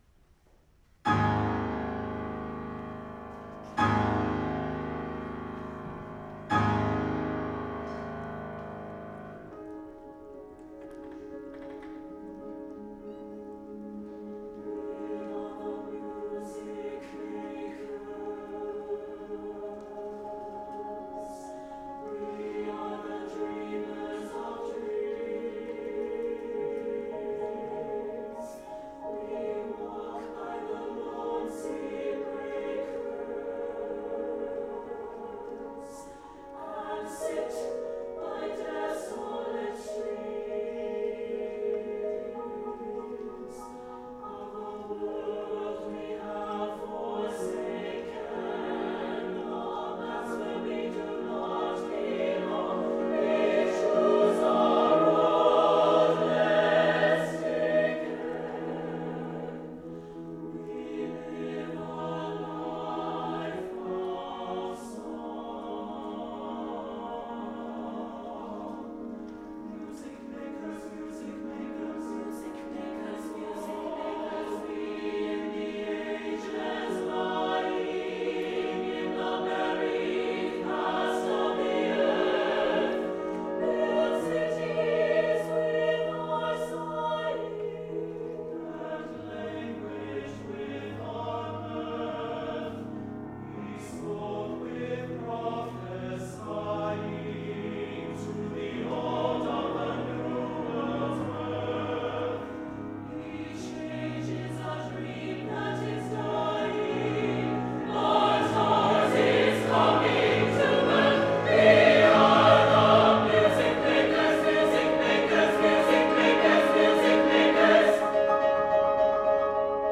for SATB Chorus and Piano (2003)